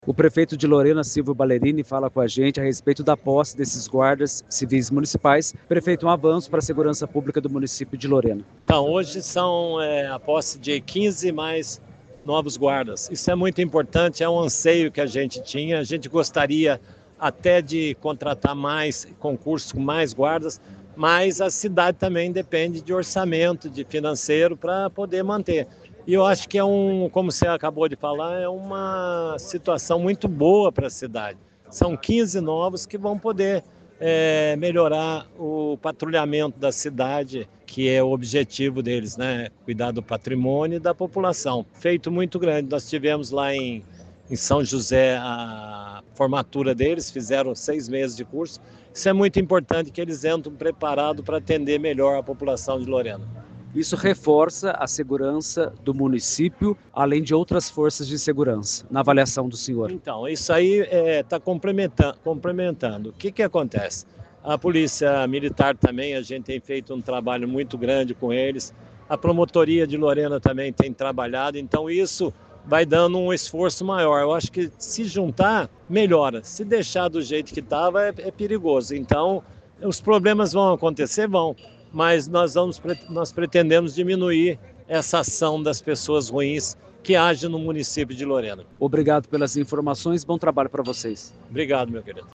Entrevistas (áudio):